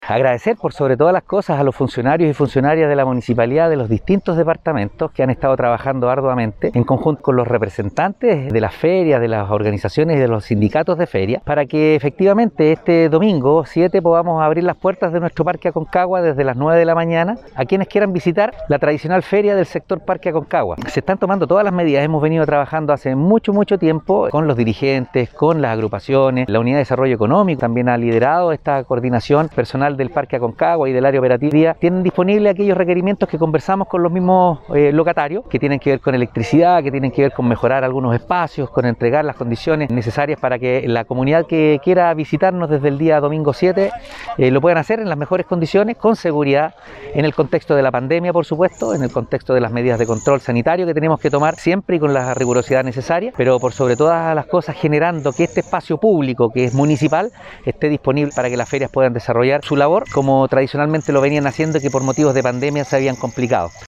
Alcalde-Oscar-Calderon-Sanchez-1.mp3